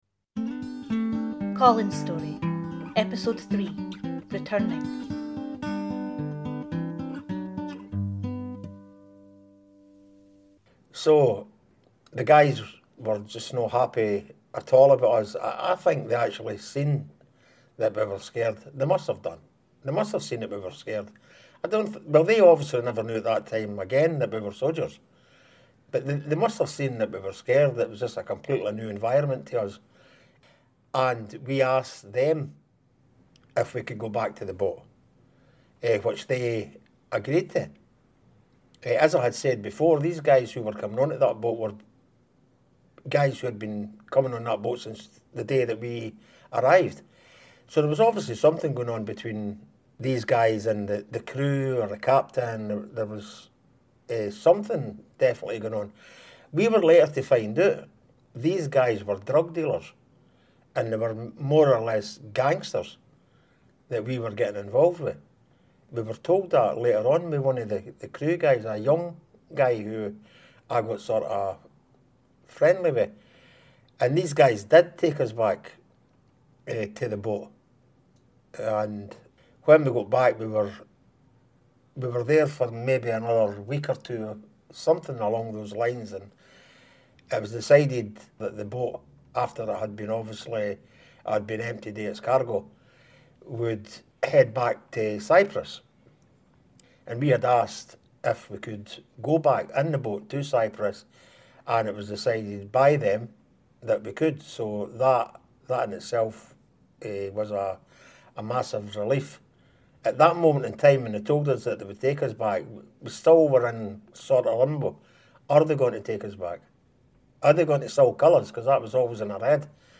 He tells his story with great candour and often, great humour.